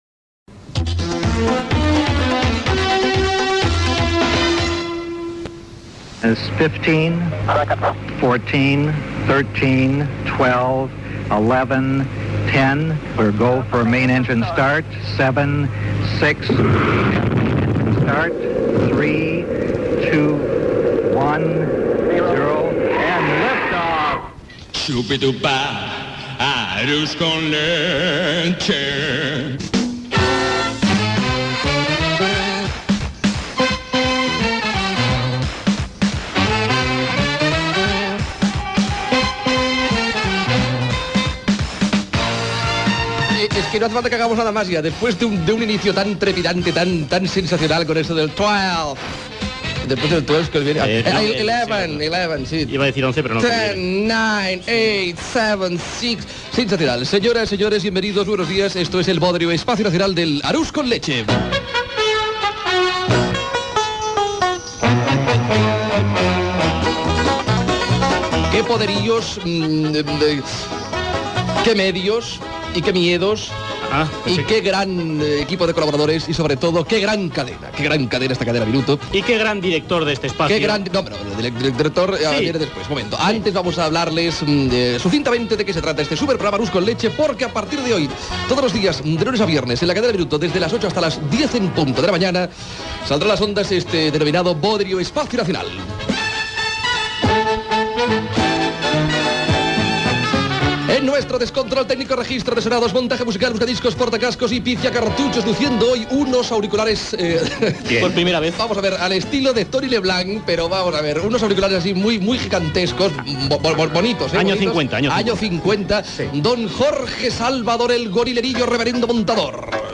Careta del programa, presentació de la primera edició a Cadena Minuto, equip del programa, comentari sobre la Cadena Minuto i la fi de l'estiu, agraïment a l'audiència, la redacció del programa.
Entreteniment
FM